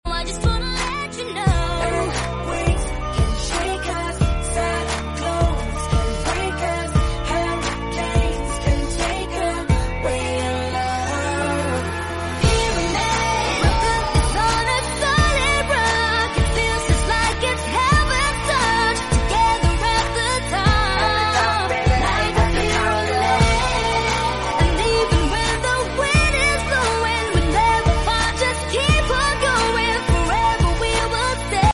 Filipino pop and R&B singer